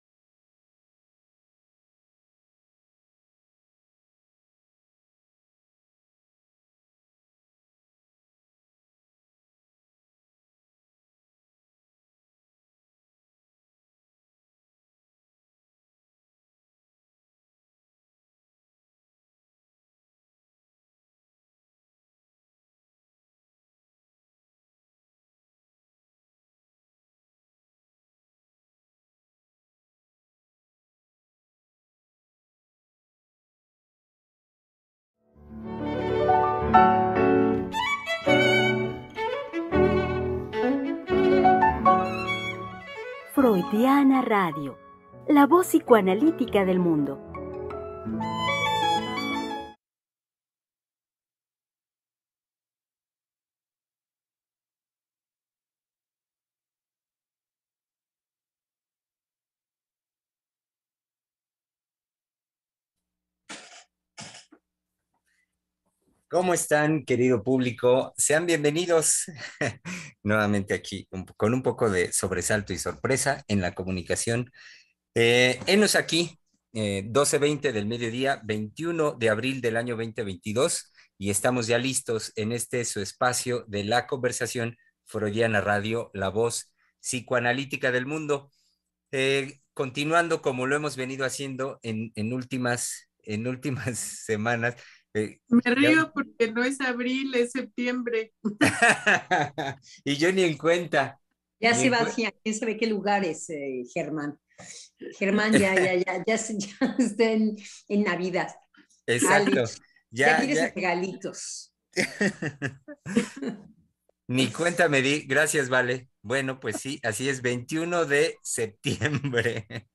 Programa transmitido el 21 de septiembre del 2022.